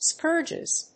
/ˈskɝdʒɪz(米国英語), ˈskɜ:dʒɪz(英国英語)/